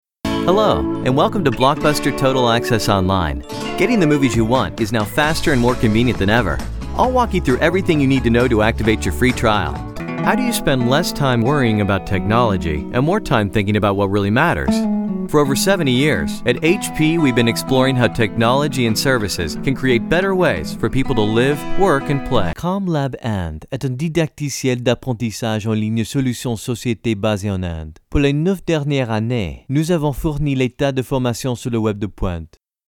COOL,REAL,FRIENDLY,SMART,POWERFUL ,FRENCH,ENGLISH,YOUNG,VOICE OVER
middle west
Sprechprobe: eLearning (Muttersprache):